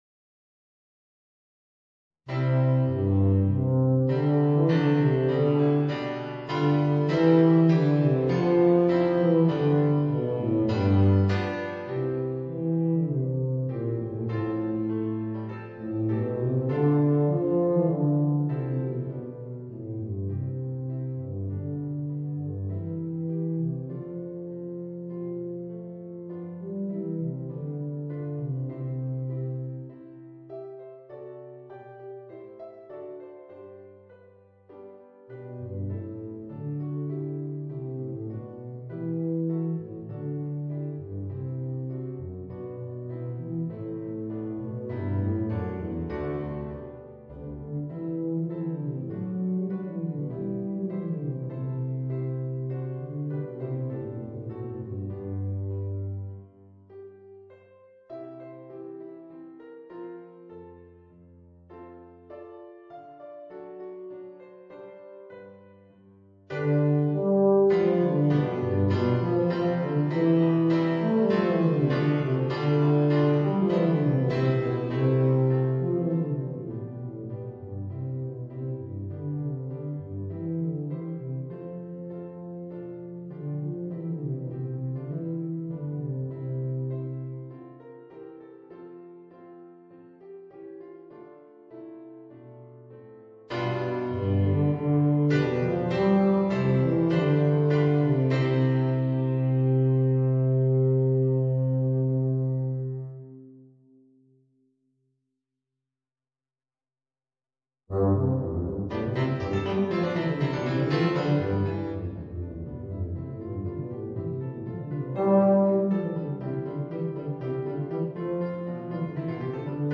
Voicing: Tuba and Organ